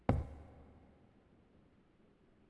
FootstepHandlerWoodl1.wav